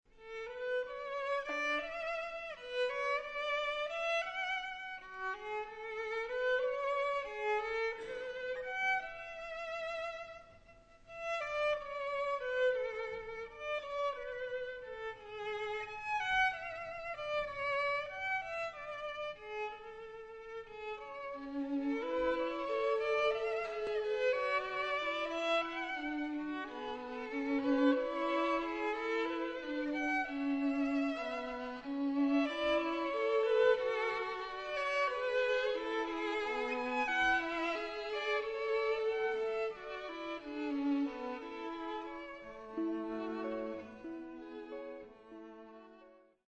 2 violins, electric guitar, cello